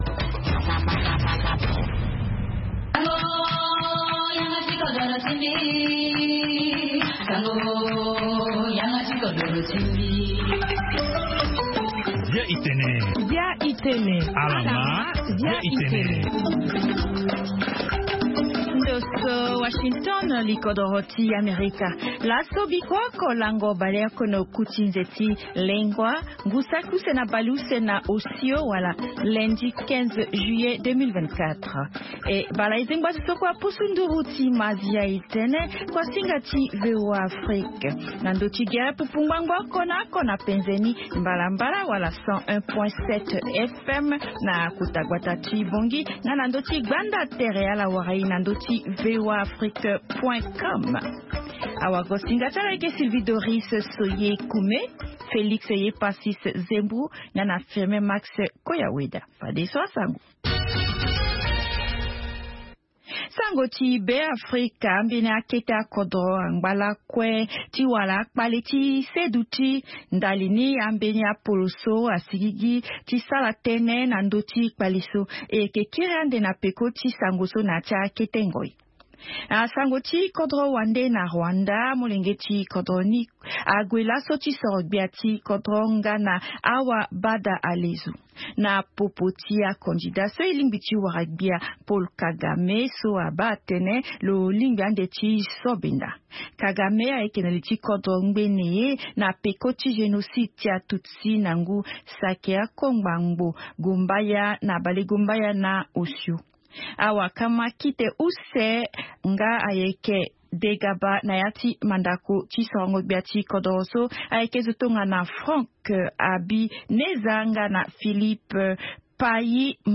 Zia i Tene, est un programme en sango comportant plusieurs rubriques sur l'actualité nationale et internationale, des interviews portant sur une analyse et des réactions sur des sujets divers ainsi qu'un volet consacré aux artistes.